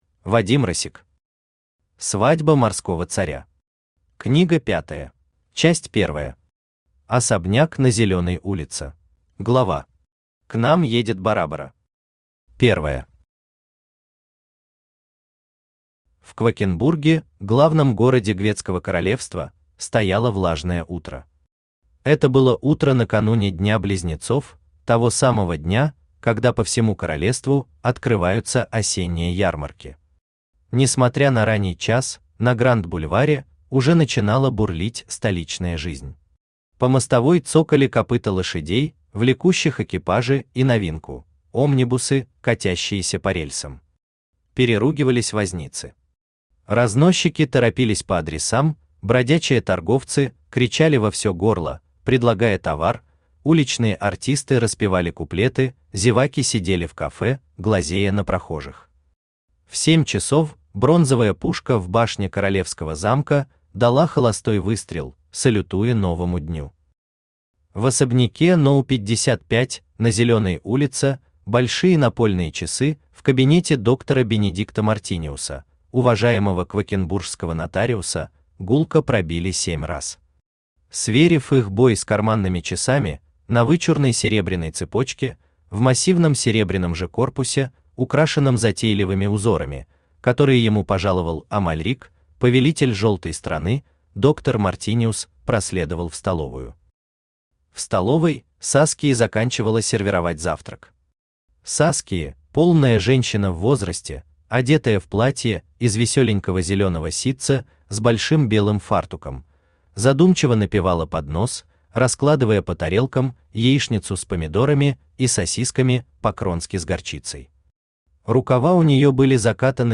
Аудиокнига Свадьба Морского царя. Книга пятая | Библиотека аудиокниг
Книга пятая Автор Вадим Россик Читает аудиокнигу Авточтец ЛитРес.